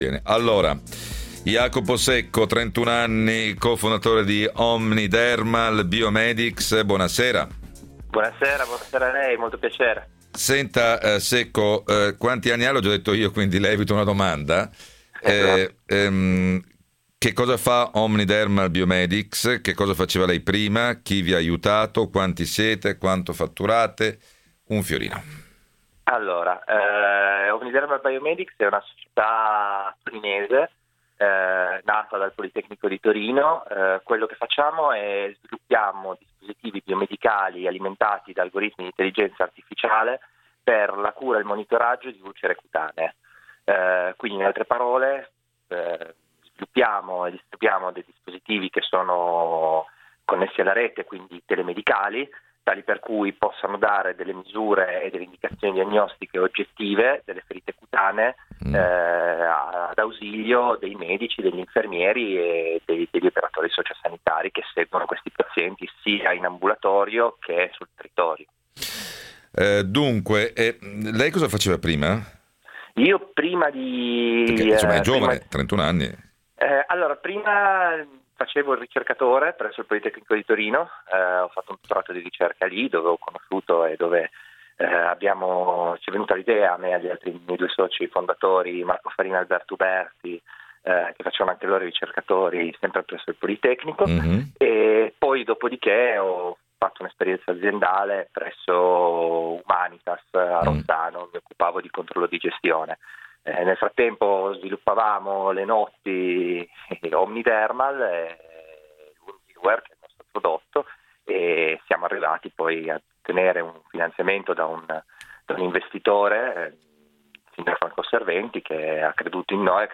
Intervista Radio 24 – Focus Economia